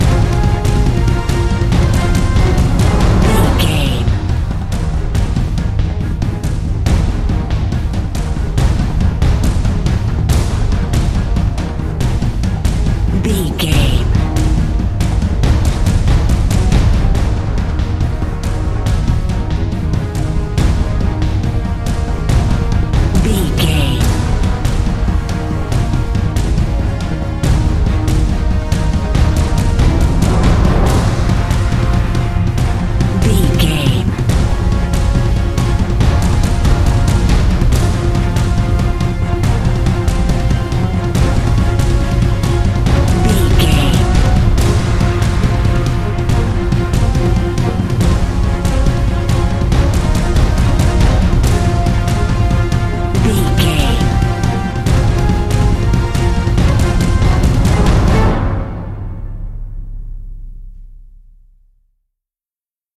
Epic / Action
Fast paced
In-crescendo
Aeolian/Minor
A♭
Fast
driving drum beat